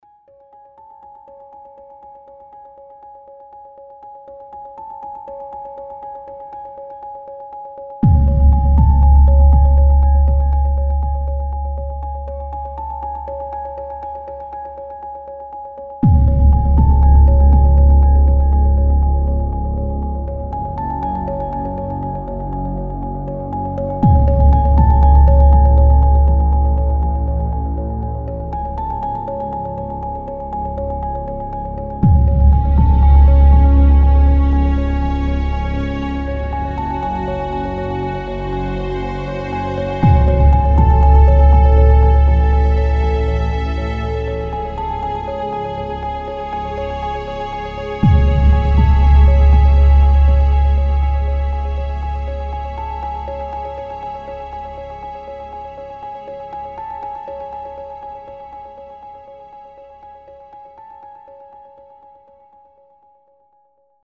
Dark minimalistic track.